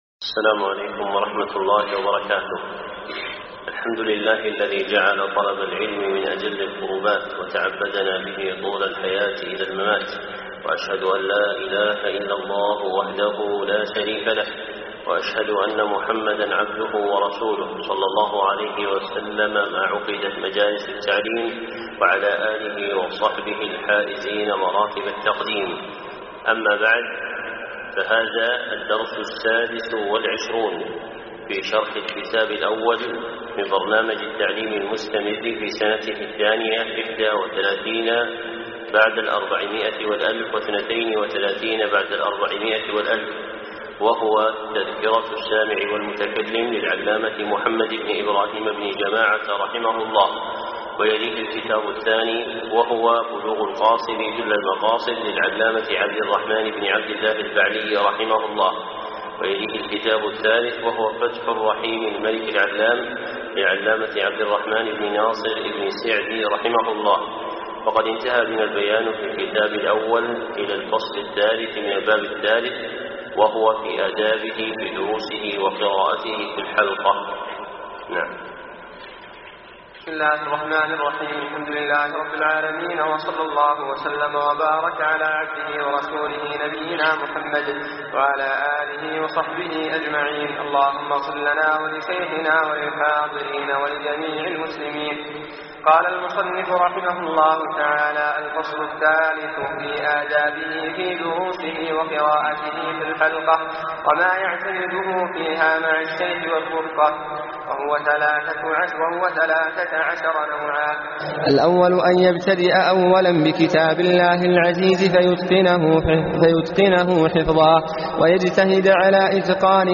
الدرس 26